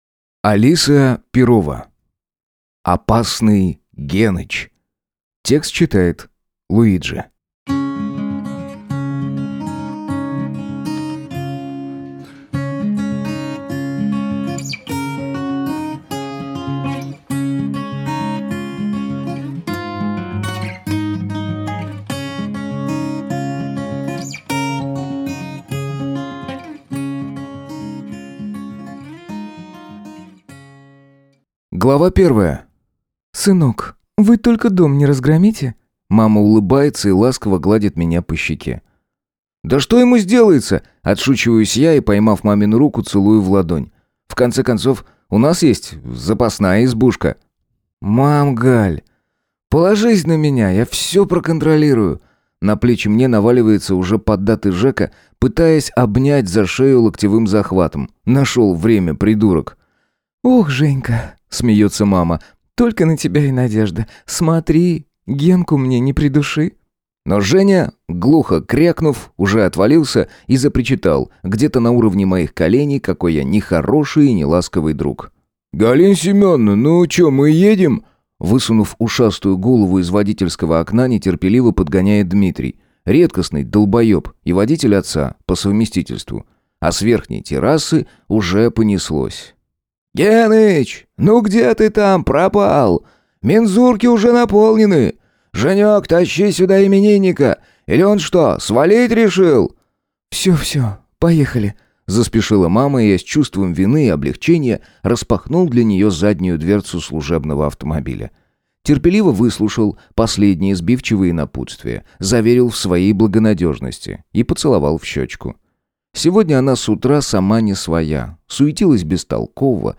Аудиокнига Опасный Геныч | Библиотека аудиокниг